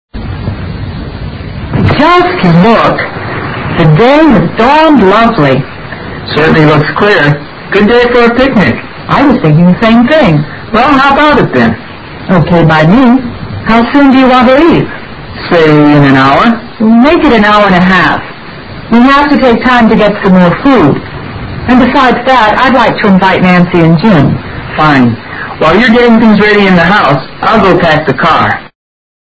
Dialogue 18